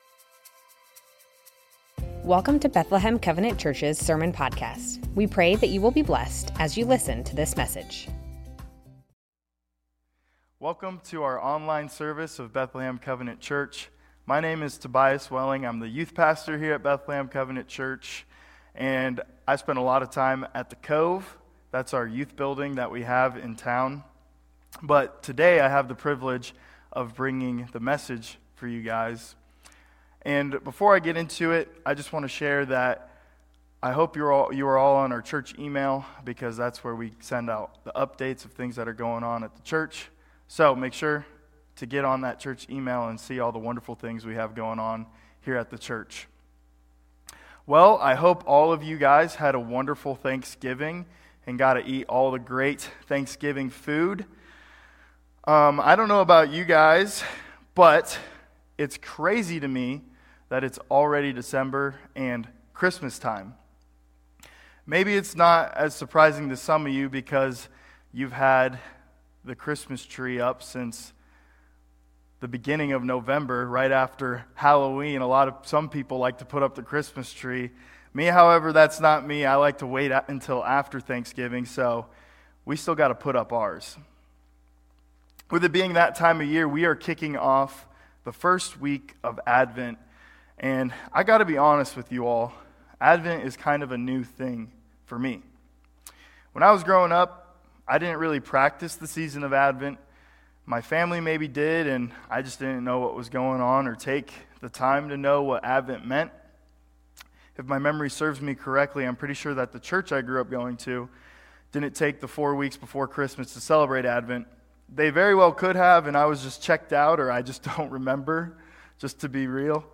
Bethlehem Covenant Church Sermons Advent: To us a Child is born Dec 01 2024 | 00:28:24 1x 00:00 / 00:28:24 Subscribe Share Spotify RSS Feed Share Link Embed